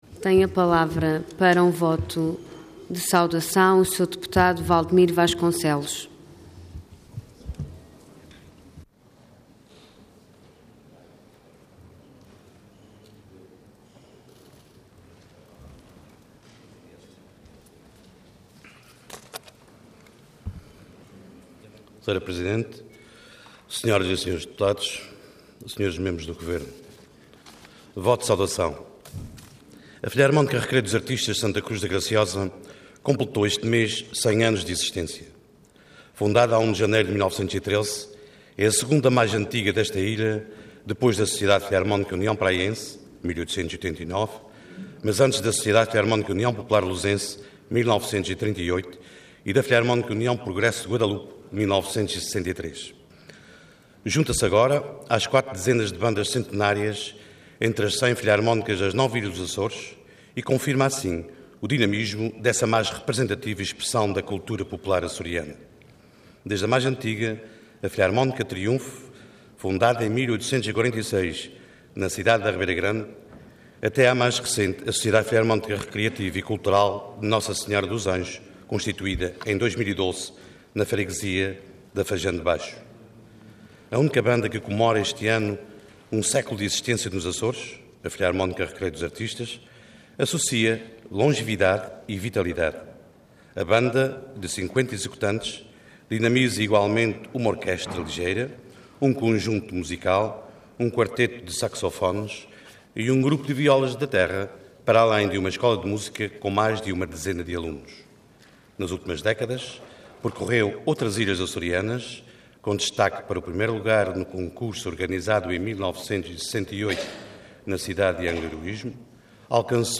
Intervenção Voto de Saudação Orador José Ávila Cargo Deputado Entidade PSD